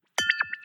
ting-dualchannel48.ogg